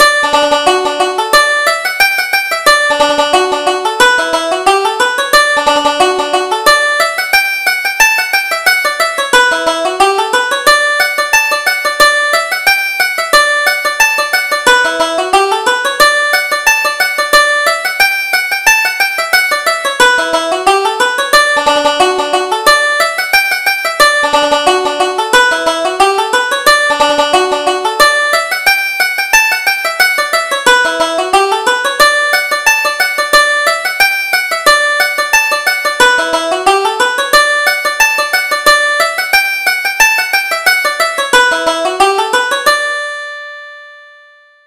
Reel: The Pure Drop